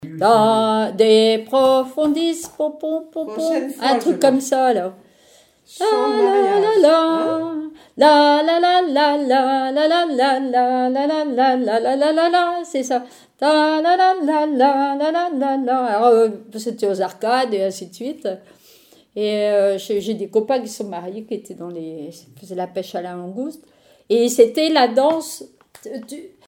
témoignages et bribes de chansons
Pièce musicale inédite